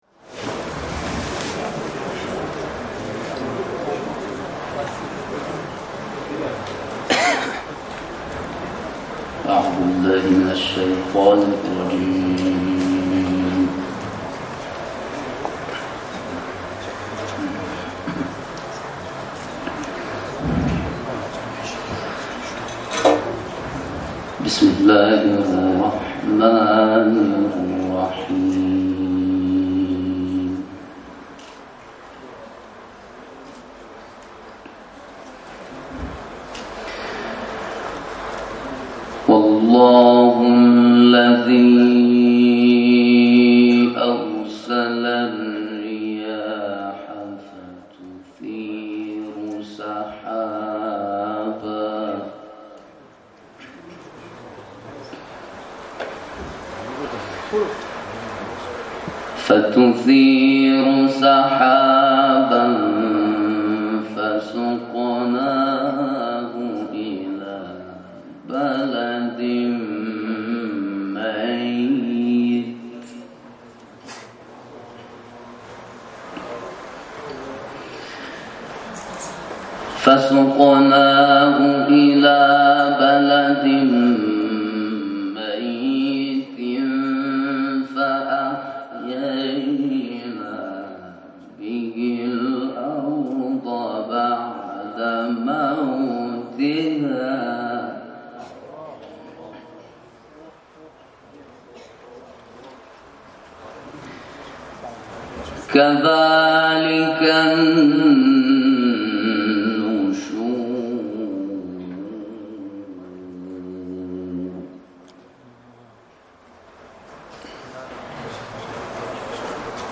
در ادامه صوت تلاوت این قاری برجسته کشورمان را می‌شنوید: